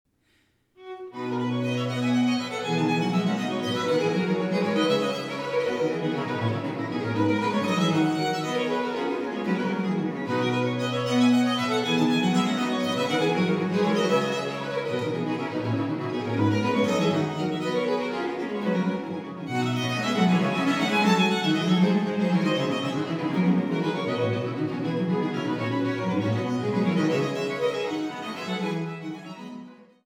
Instrumetalmusik für Hof, Kirche, Oper und Kammer